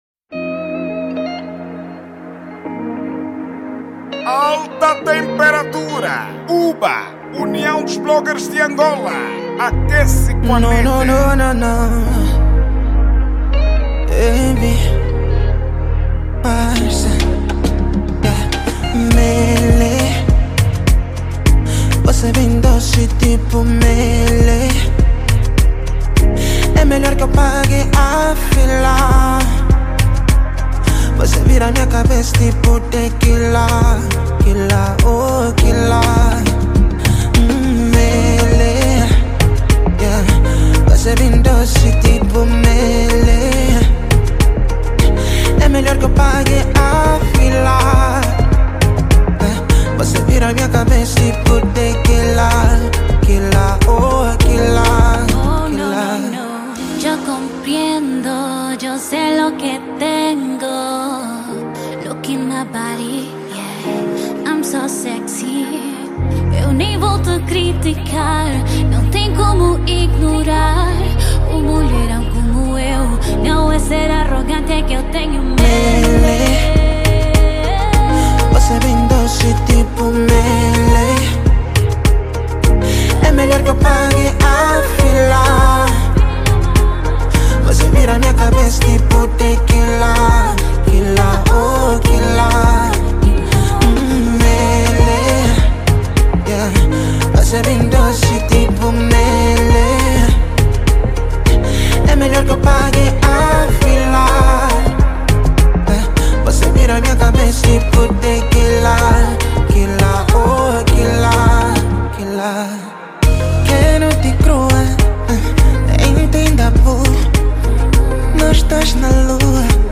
Gênero: Dance Hall